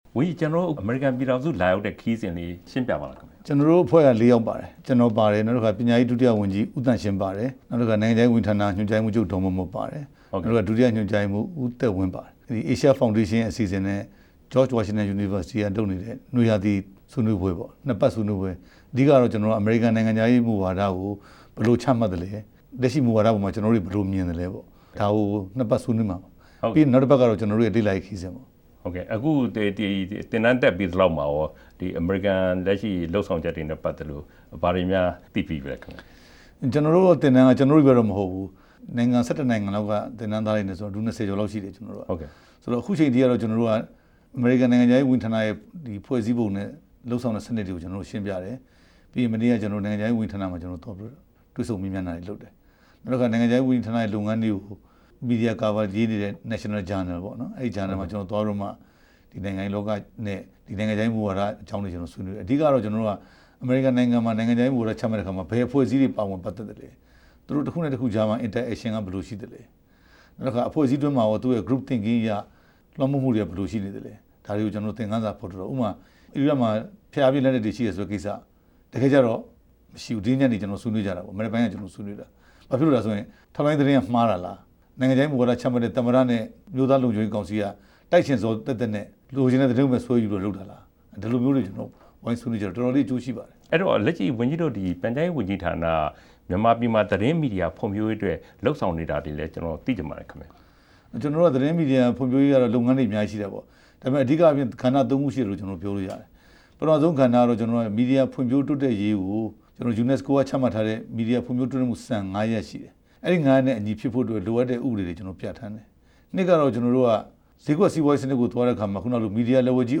ပြန်ကြားရေး ဒု ဝန်ကြီး ဦးရဲထွဋ်နဲ့ မေးမြန်းချက်